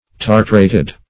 Search Result for " tartrated" : The Collaborative International Dictionary of English v.0.48: Tartrated \Tar"tra`ted\, a. (Med.